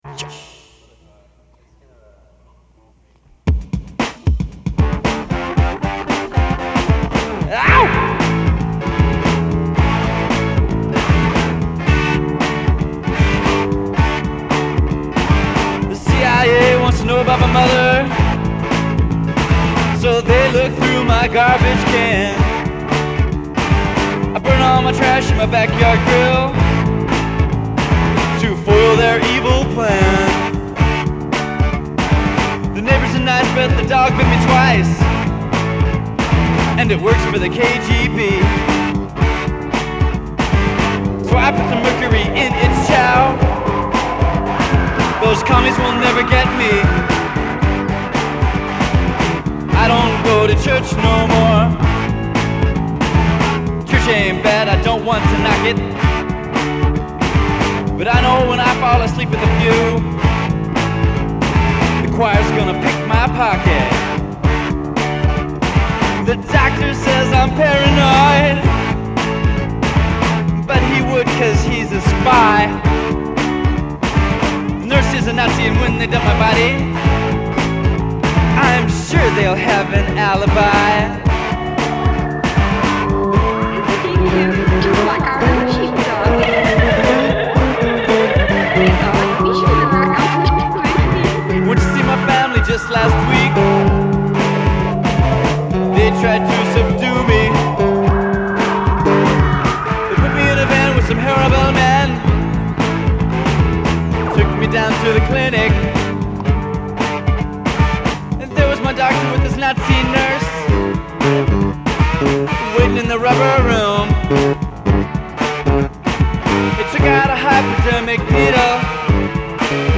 This was a weird late-night jam I think I added stuff to in the computer.